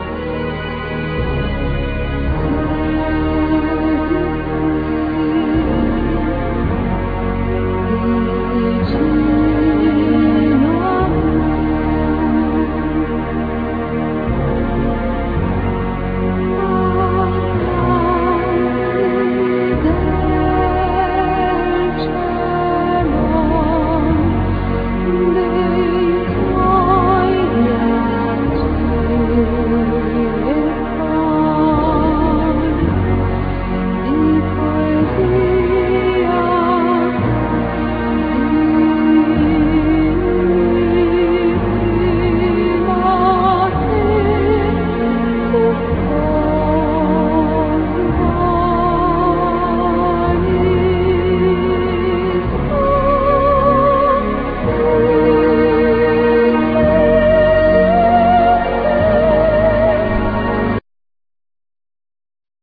Voice,Keyboards,Percussions
Keyboards,Voice,Bass,Programming
Flute
Oboe
Percussions,Ocean drums
Violin